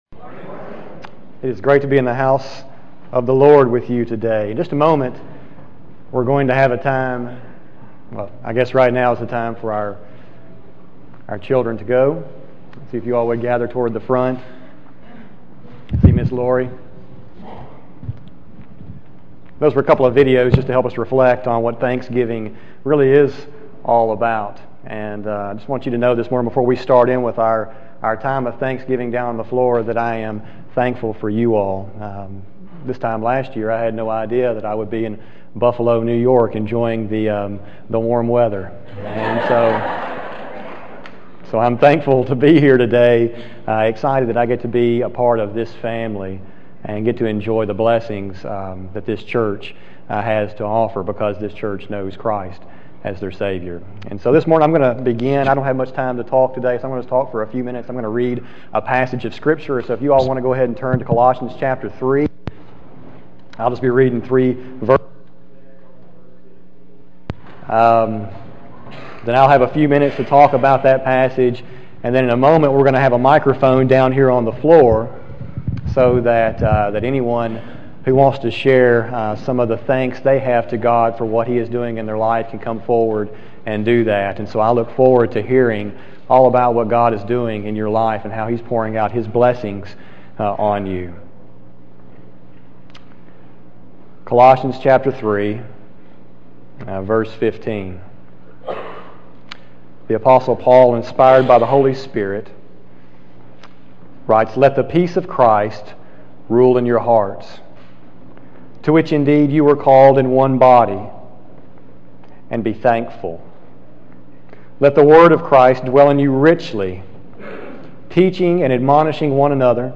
This was a unique service that allowed different people in Church that day to come up and say what they are thankful for.